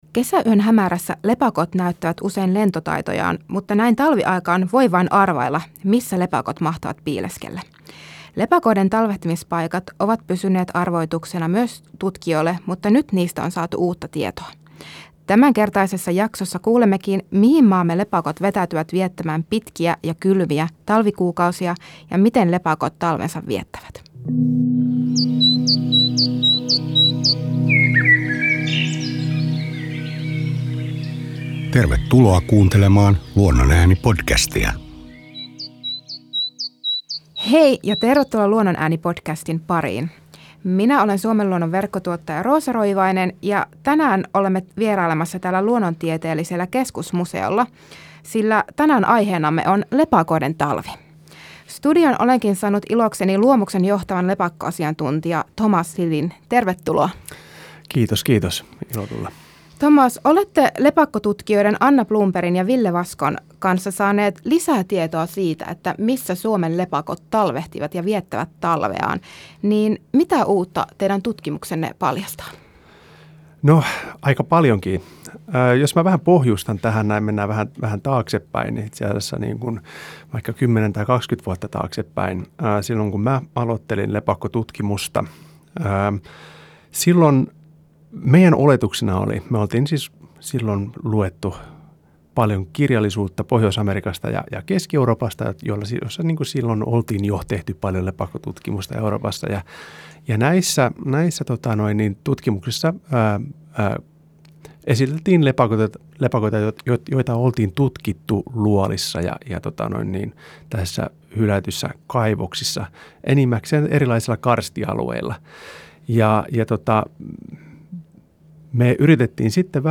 Tällä kertaa myös lukijamme pääsivät esittämään kysymyksiä. Lepakkoasiantuntija vastaa muun muassa siihen, miksi lepakoita voi nähdä keväällä keskellä kirkasta päivää.